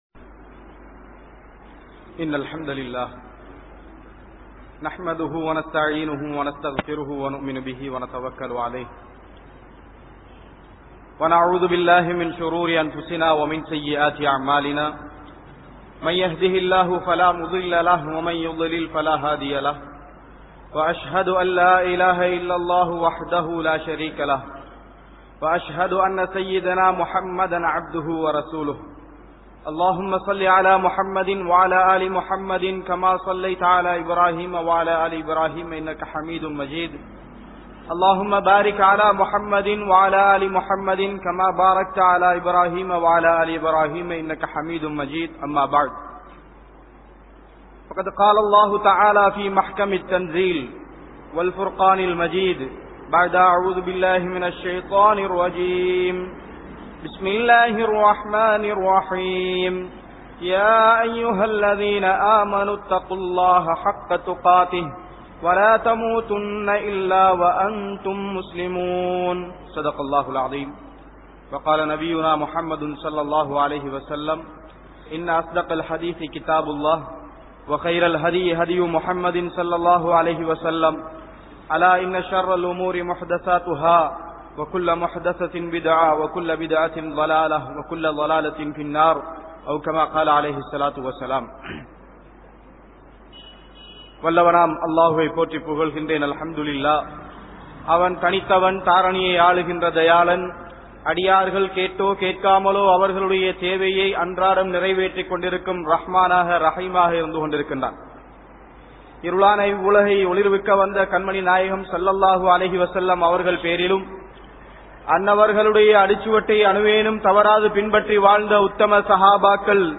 Thaqwa | Audio Bayans | All Ceylon Muslim Youth Community | Addalaichenai